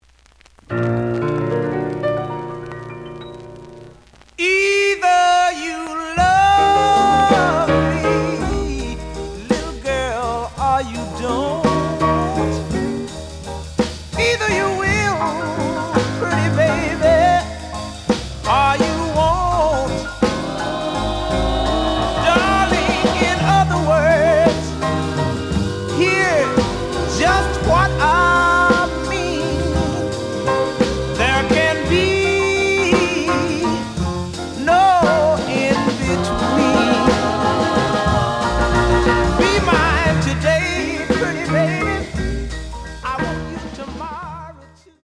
The audio sample is recorded from the actual item.
●Genre: Soul, 60's Soul
Slight noise on beginning of both sides, but almost good.)